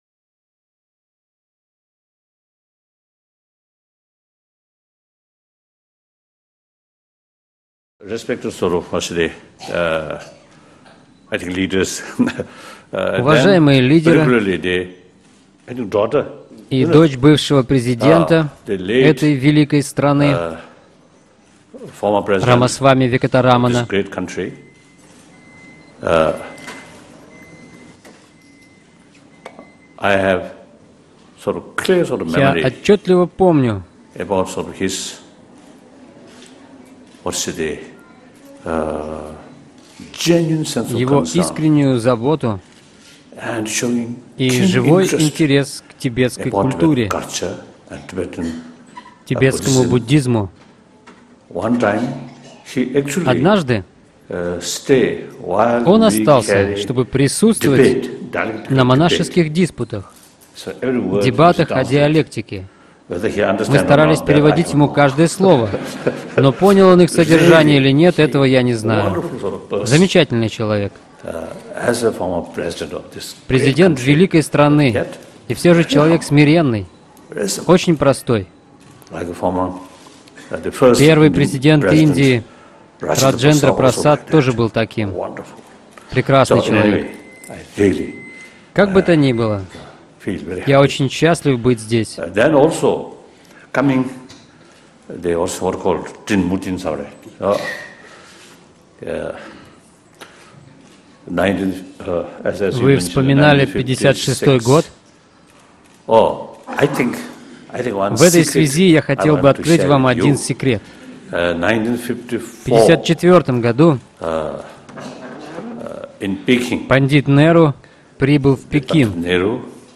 Аудиокнига Ненасилие и духовные ценности | Библиотека аудиокниг